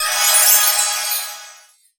healing_magic_spell_03.wav